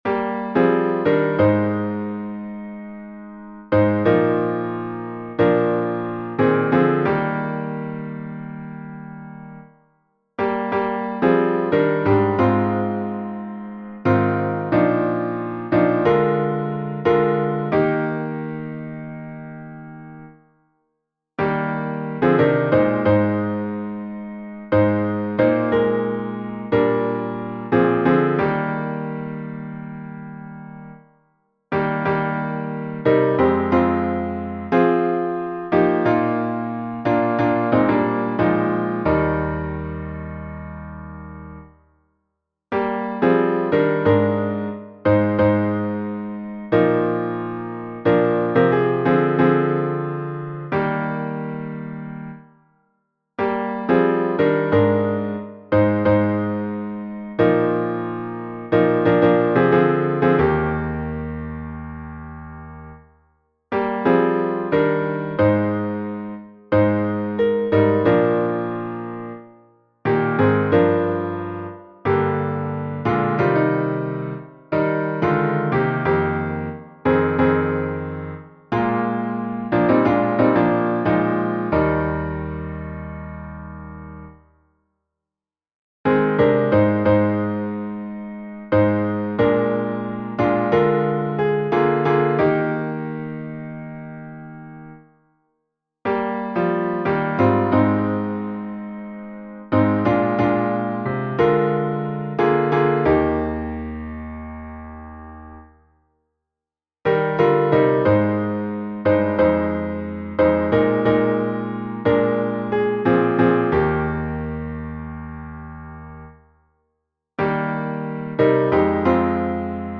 Original hymn